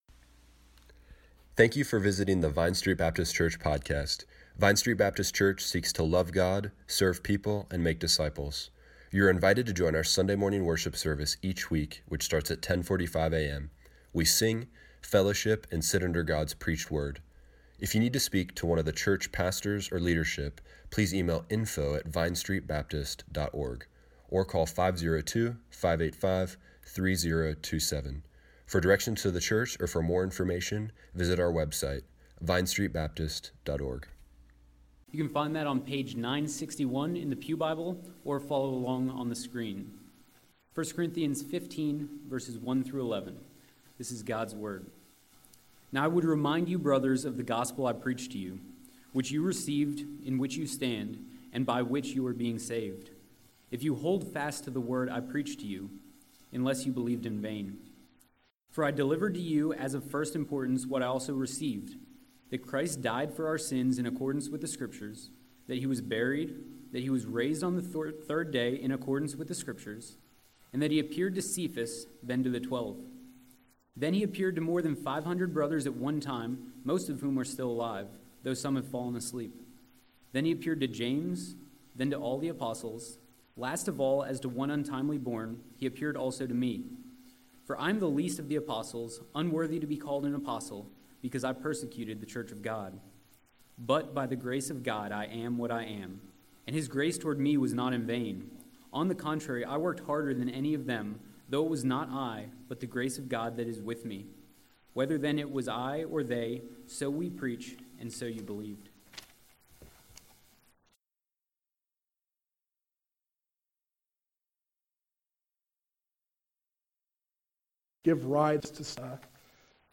March 18, 2018 Morning Worship | Vine Street Baptist Church